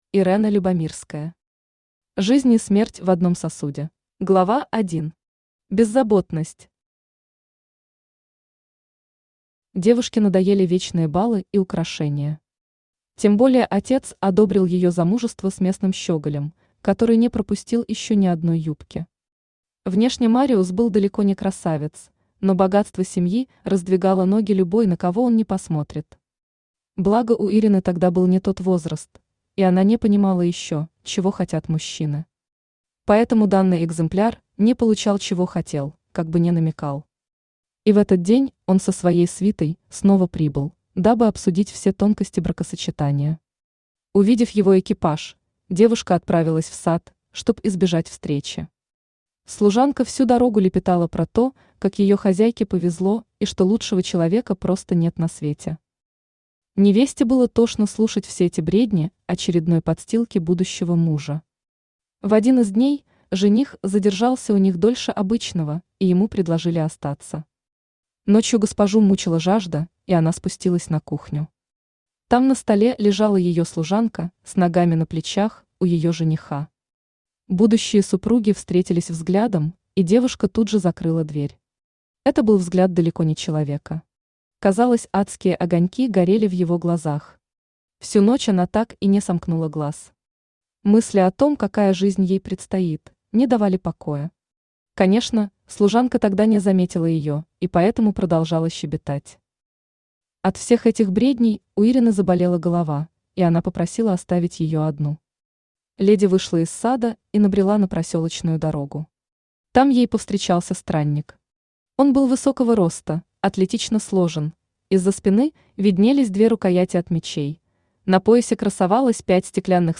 Аудиокнига Жизнь и смерть в одном сосуде | Библиотека аудиокниг
Aудиокнига Жизнь и смерть в одном сосуде Автор Ирена Любомирская Читает аудиокнигу Авточтец ЛитРес.